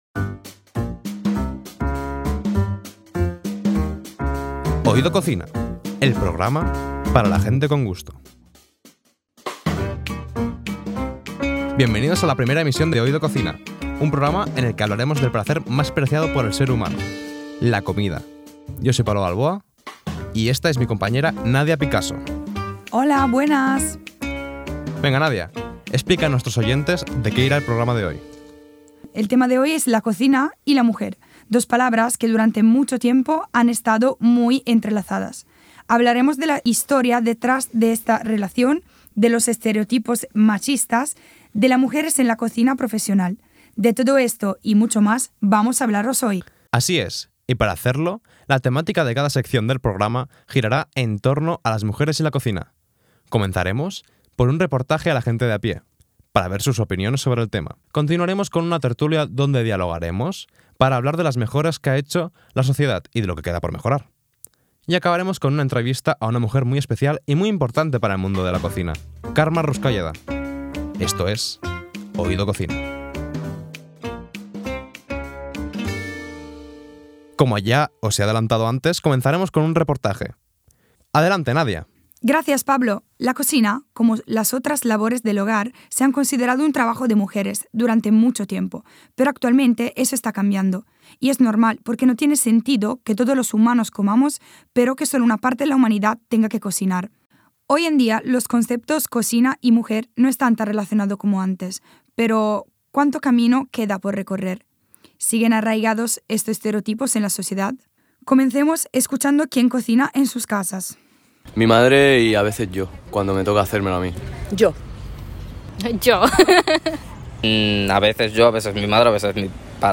Podcast sobre el rol de las mujeres en la cocina. Con entrevista a Carme Ruscalleda.